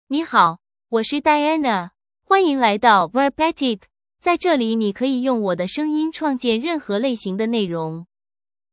Diana — Female Chinese (Mandarin, Simplified) AI Voice | TTS, Voice Cloning & Video | Verbatik AI
Diana is a female AI voice for Chinese (Mandarin, Simplified).
Voice: DianaGender: FemaleLanguage: Chinese (Mandarin, Simplified)ID: diana-cmn-cn
Voice sample
Diana delivers clear pronunciation with authentic Mandarin, Simplified Chinese intonation, making your content sound professionally produced.